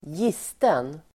Ladda ner uttalet
gisten adjektiv, dried to the point of cracking , leaky Uttal: [²j'is:ten] Böjningar: gistet, gistna Synonymer: murken, rutten Definition: otät, söndertorkad Exempel: en gisten eka (a leaky rowing boat)